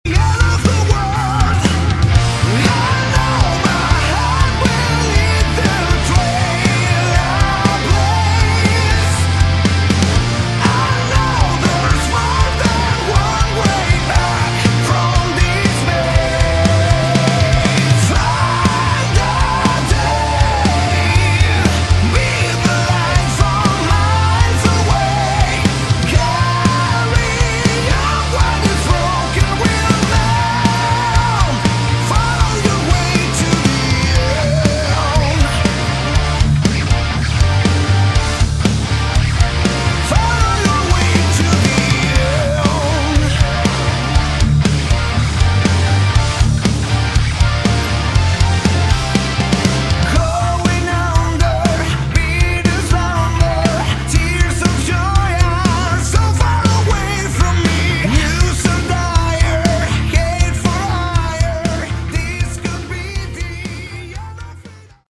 Category: Melodic Metal
vocals
guitar
keyboards, backing vocals
bass
drums